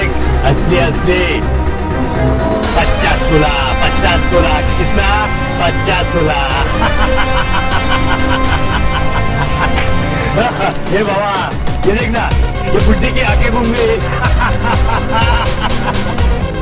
Category: Dailog